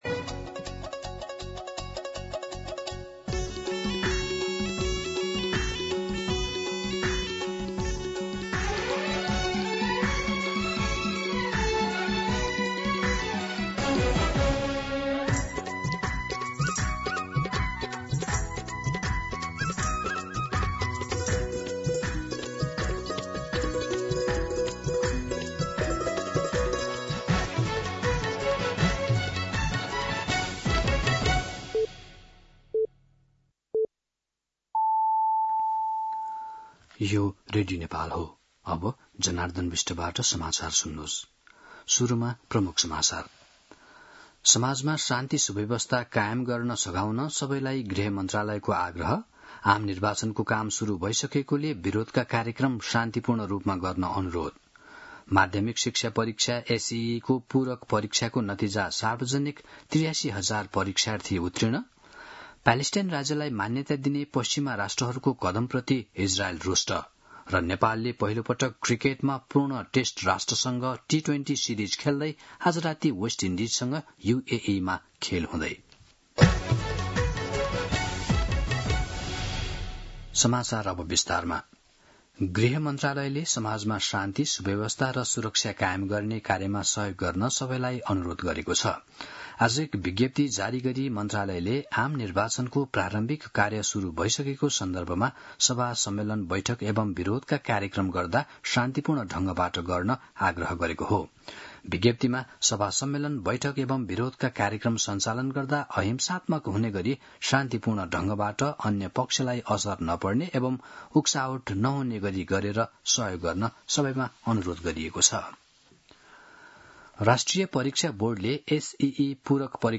दिउँसो ३ बजेको नेपाली समाचार : ११ असोज , २०८२
3-pm-Nepali-News-4.mp3